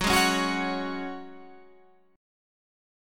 F#sus2 chord